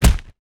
body_hit_small_11.wav